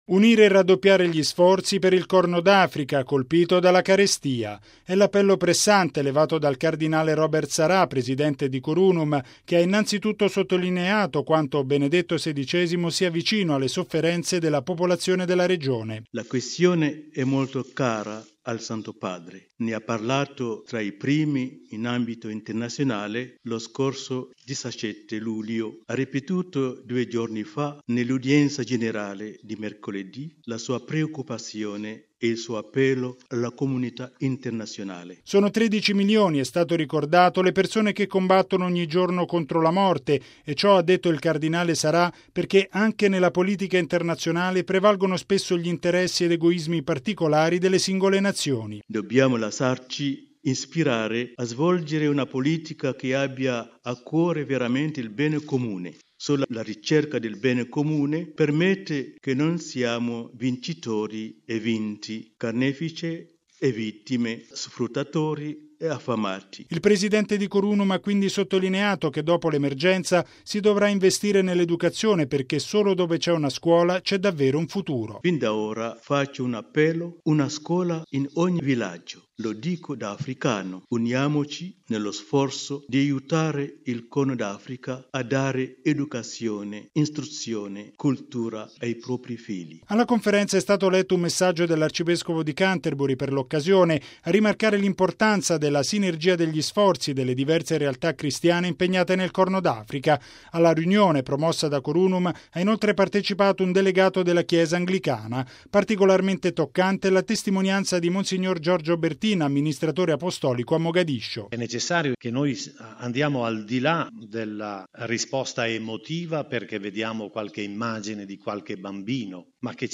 ◊   Si è tenuta stamani, presso la Sala Stampa della Santa Sede, una conferenza stampa sull’emergenza umanitaria nel Corno d’Africa, al termine di una riunione promossa dal Pontificio Consiglio “Cor Unum”.